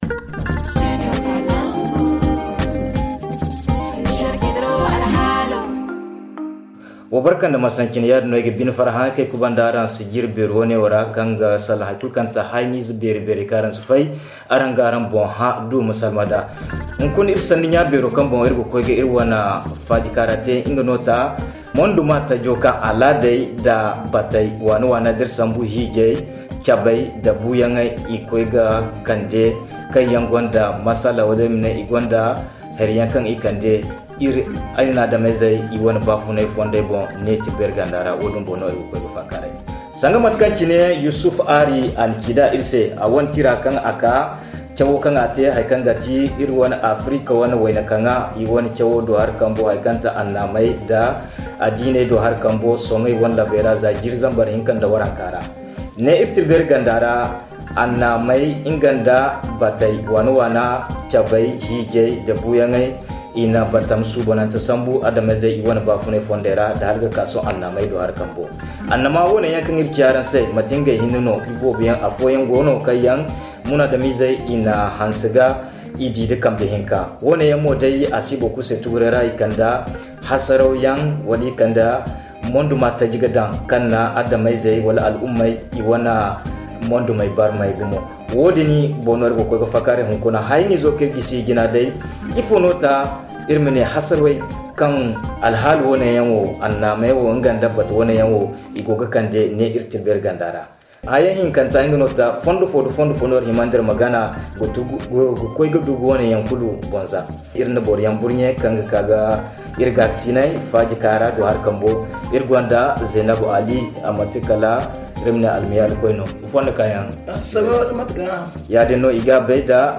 père de famille
mère de famille
prédicateur ZA Le forum en zarma Télécharger le forum ici.